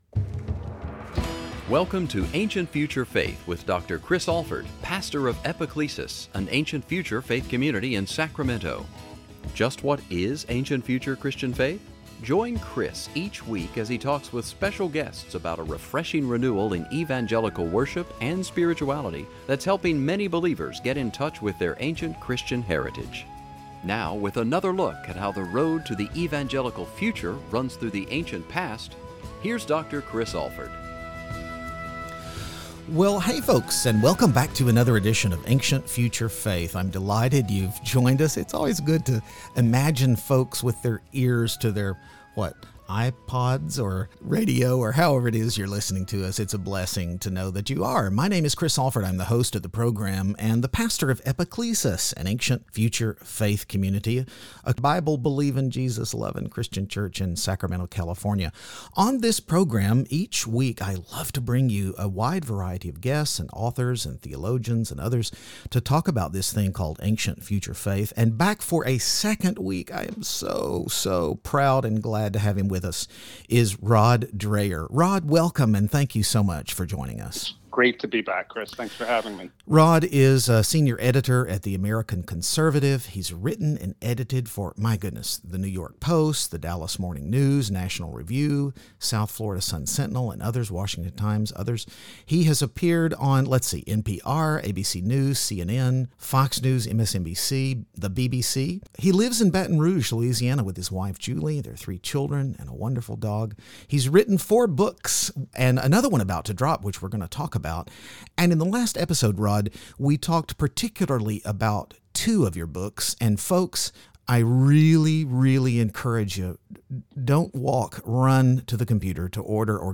Back for a second time in the Ancient-Future Faith studio, bestselling author Rod Dreher talks about Covid and the church. How will the pandemic and the subsequent rules and guidelines affect liturgy or worship? Will current events shape the church of the future?